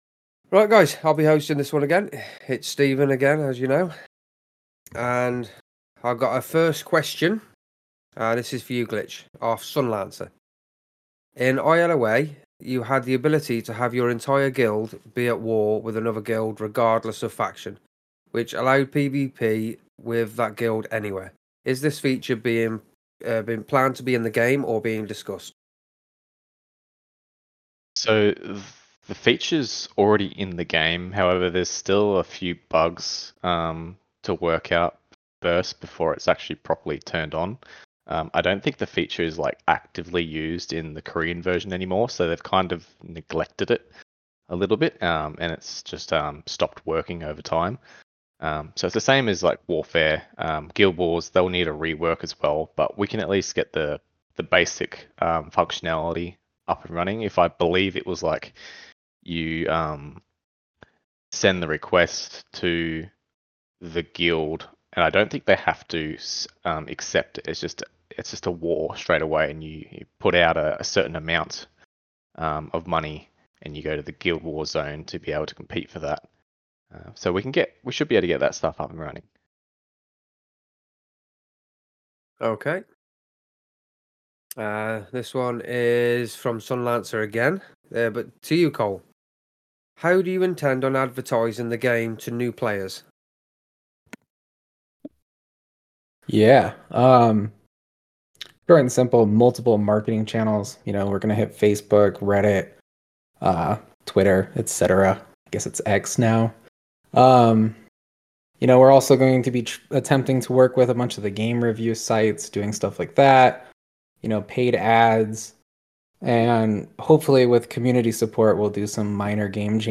Picture a cozy virtual gathering, where we discuss everything from recent updates to future plans. It's a chance for you to hear directly from the team and ask those burning questions you've been saving up.